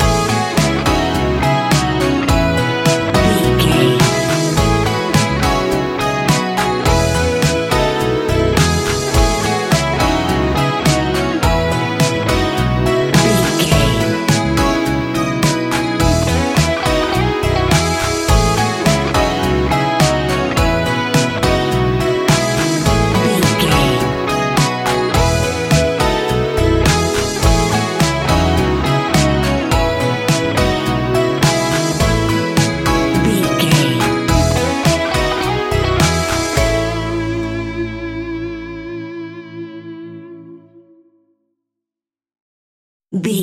Ionian/Major
ambient
new age
downtempo